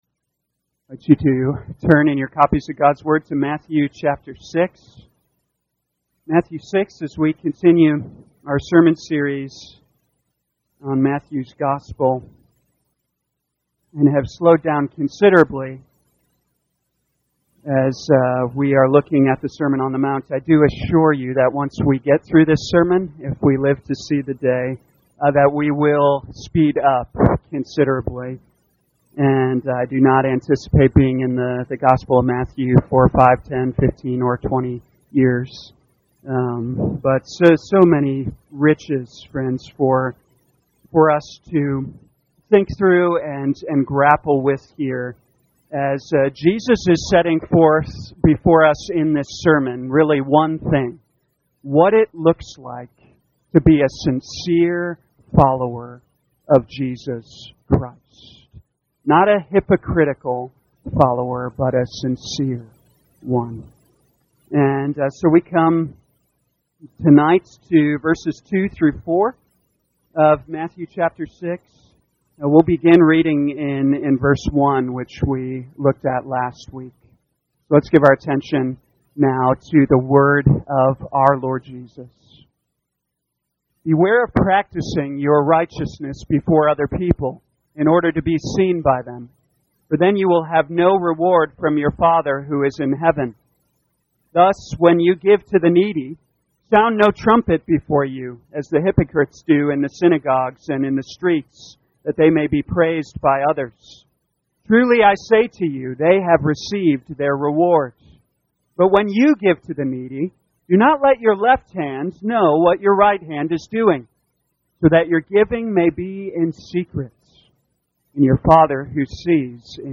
2023 Matthew Evening Service Download